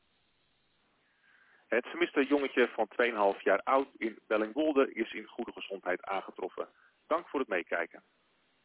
Burgernet heeft een audioboodschap ingesproken bij deze melding.